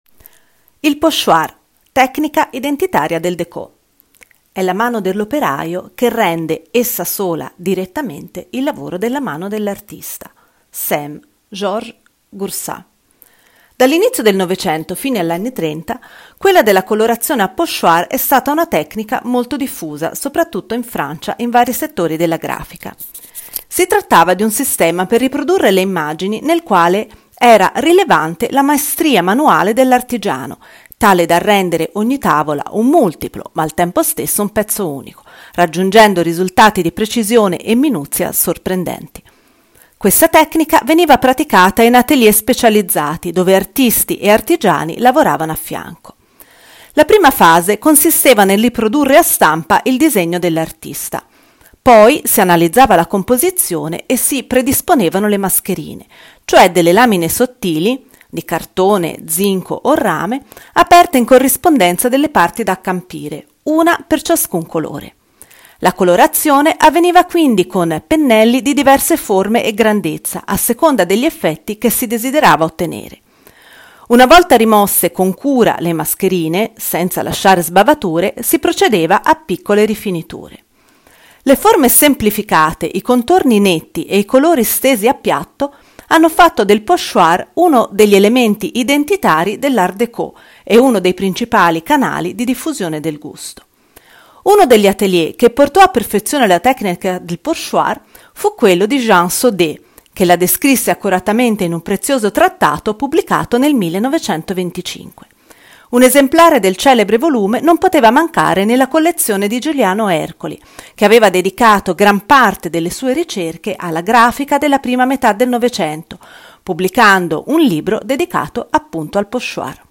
AUDIOGUIDA MOSTRA